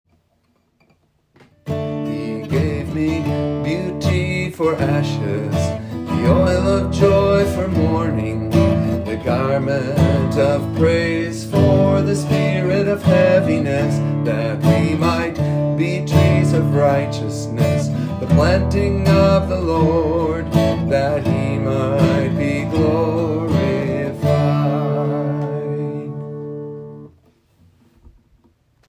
{Key:D}